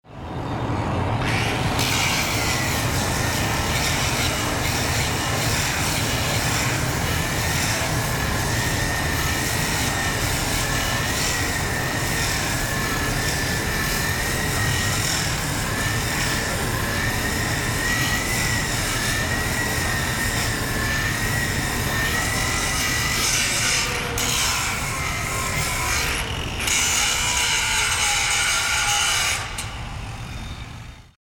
機械のモーター音やアナウンス、発車の合図......。地下鉄、都電荒川線、都営バスのそれぞれの場所でしか聞くことができない音を収録しました。
第9回地下鉄大江戸線「レール研磨音」
第9回 地下鉄大江戸線「レール研磨音」 レール交換をする保線作業時の音。レール同士の溶接部分が平らになるよう、工具の一種「電動サンダー」で研磨します。